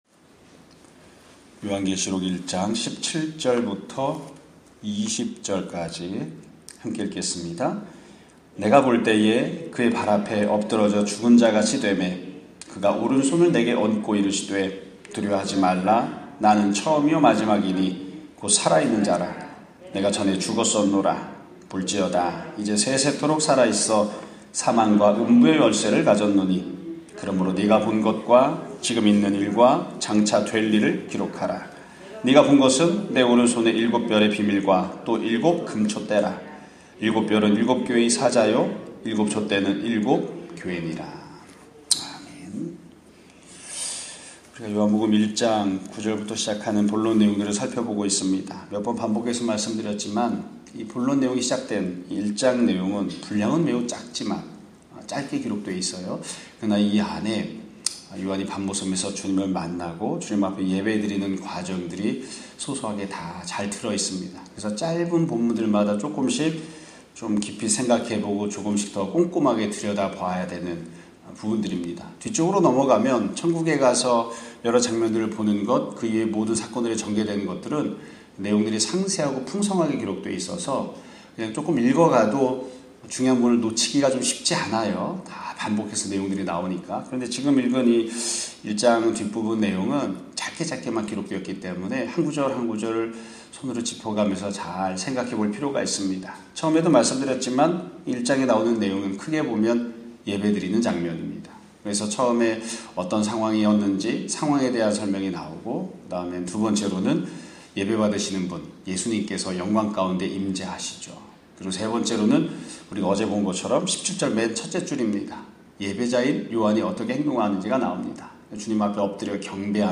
2022년 10월 26일(수요일) <아침예배> 설교입니다.